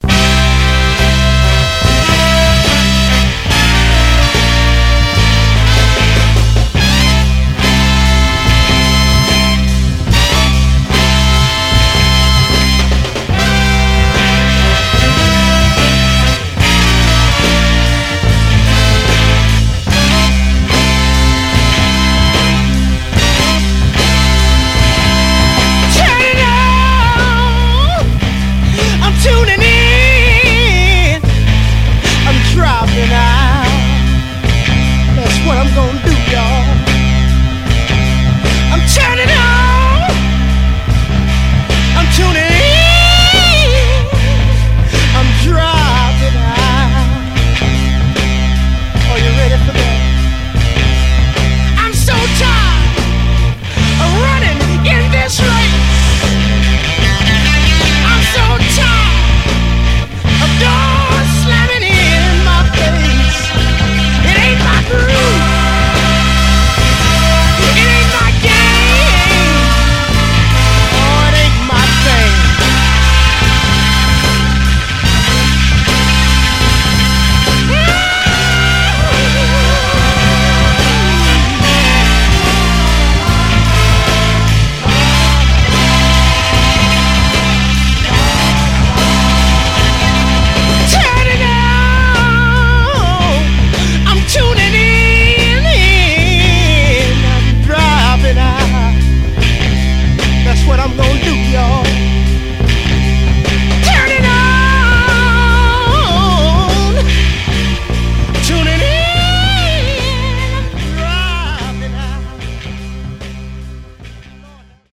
盤はA面に細かいキズがありますが、音への影響はあまり無くプレイ問題ありません。
※試聴音源は実際にお送りする商品から録音したものです※